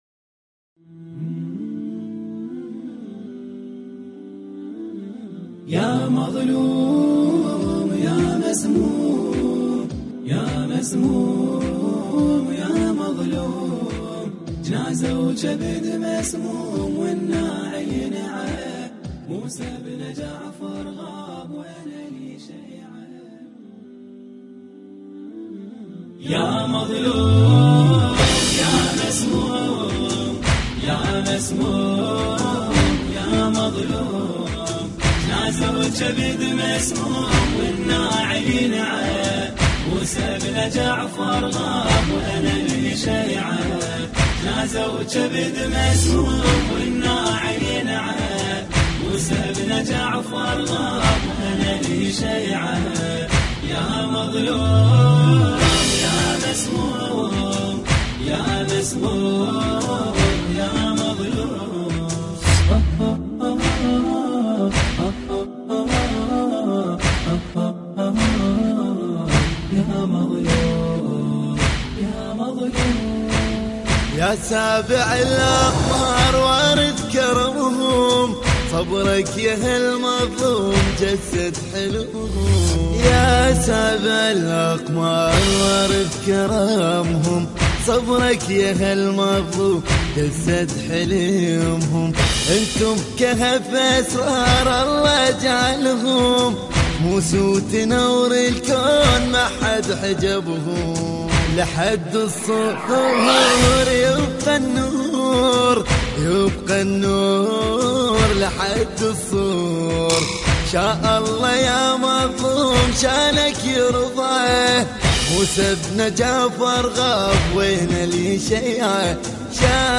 مراثي الامام الكاظم (ع)